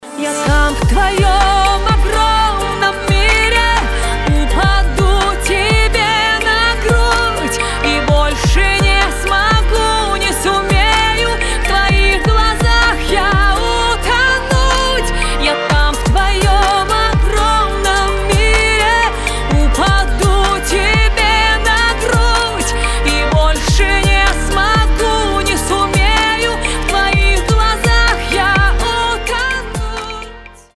поп
красивые
женский вокал
эстрадные